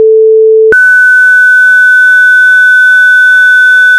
Я взяв чисту синусоїду 440 Гц — стерильну, без тертя, ідеальну — і сказав їй пам’ятати.
Те, що ви чуєте на позначці 0.724, не є помилкою.
Коли коефіцієнт досягає піку, сигнал сповільнюється.
Потім глибина бітів руйнується. 4-бітне стиснення.
soundscape aiethics recursive analog decay